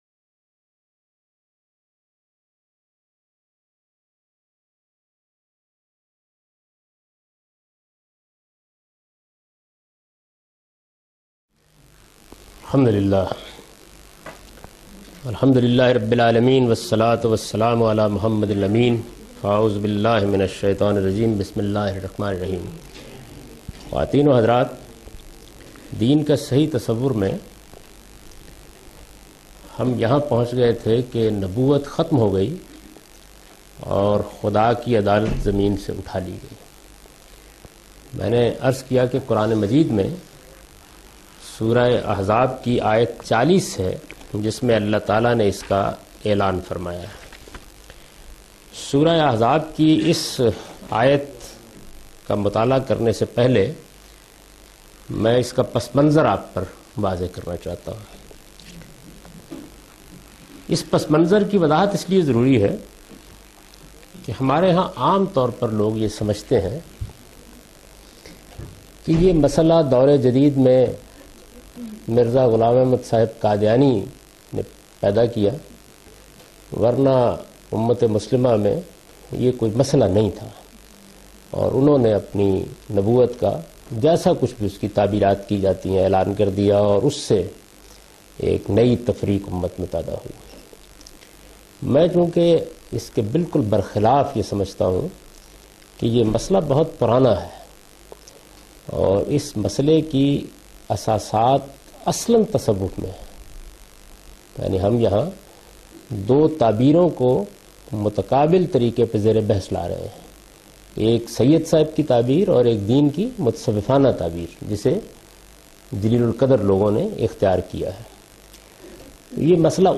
The book presents his understanding of Islam, resulting from a lifetime’s research and deliberations on the Quran, the Sunnah, and the Hadith. In this lecture series, he not only presents his interpretation of these sources, but compares and contrasts his opinions with other major schools developed over the past 1400 years. In this lecture he teaches the topic 'The True Religion'.(Lecture no. 10– Recorded on 13th September 2002)